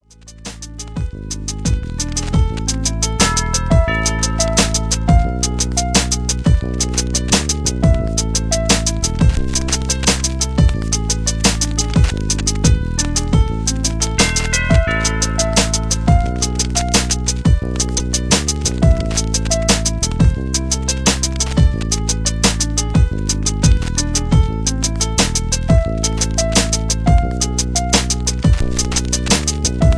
backing tracks
rap